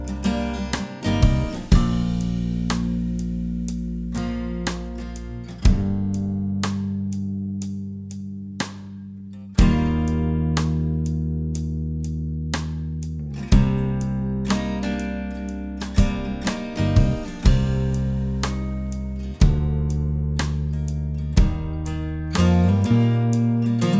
Clean Rock 5:14 Buy £1.50